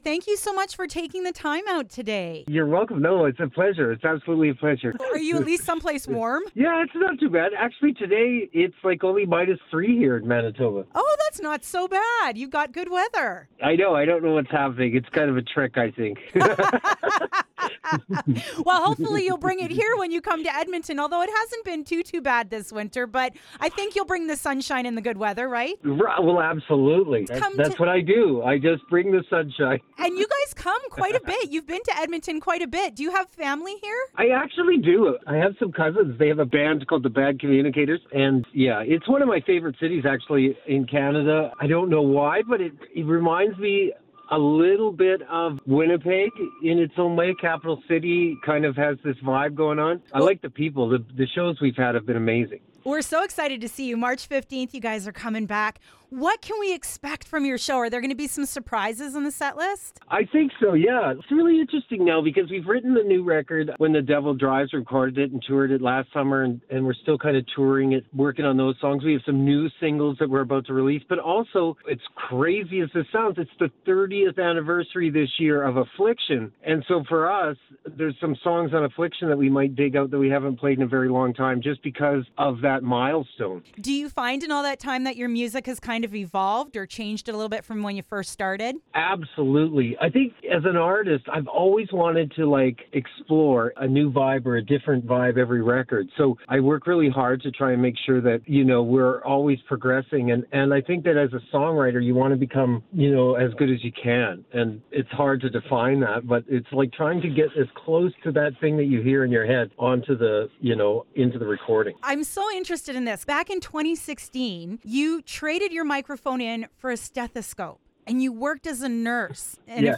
Econoline Crush Returns to Edmonton: A Conversation with Trevor Hurst